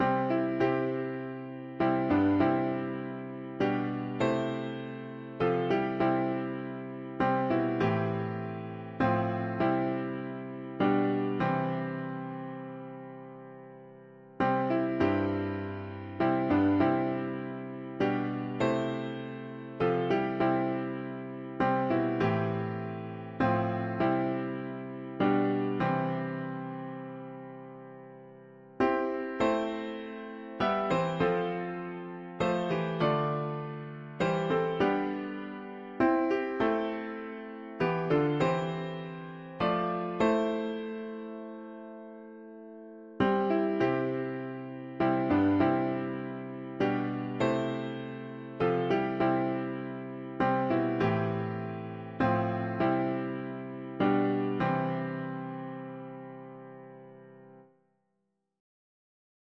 And gathered by most fragile pow’… english secular 4part chords
Music: North American Traditional
Key: C major